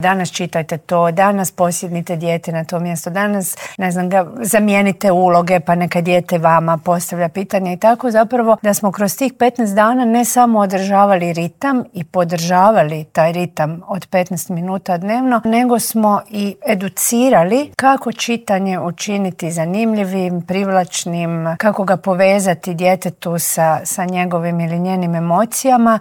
Nacionalni čitalački izazov "15 po 15 - cijela Hrvatska čita djeci" polučio je odlične rezultate, otkrila je u Intervjuu tjedna Media servisa ministrica kulture i medija Nina Obuljen Koržinek.